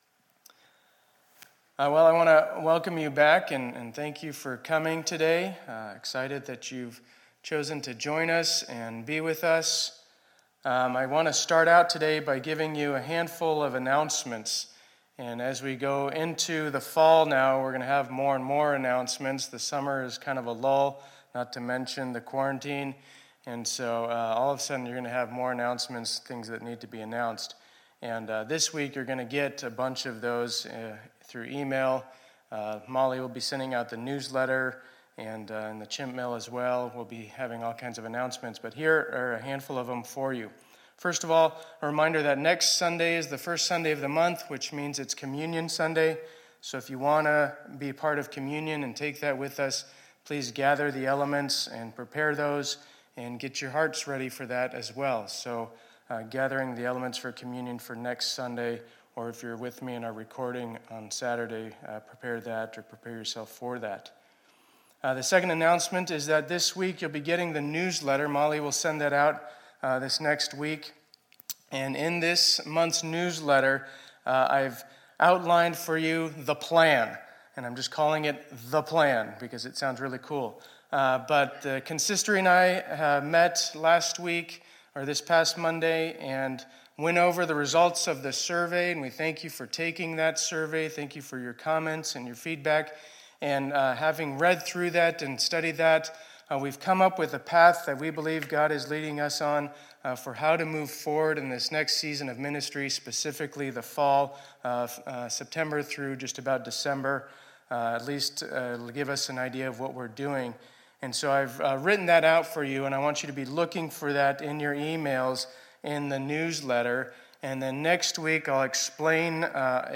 2020-08-30 Sunday Service